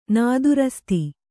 ♪ nādurasti